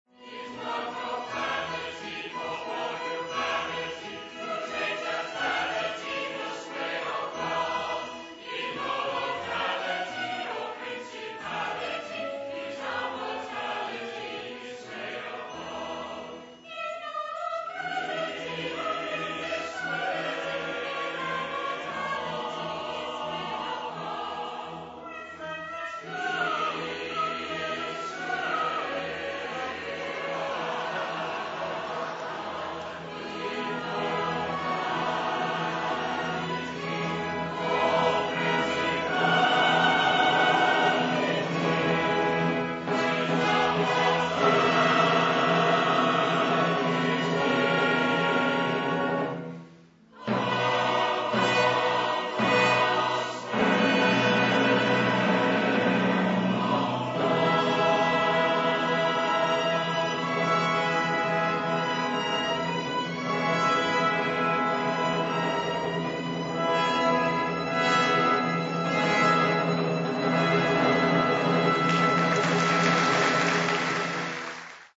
Jeeves Audio Services is pleased to be associated with the Gilbert and Sullivan Society of Victoria, making live recordings of the society's productions.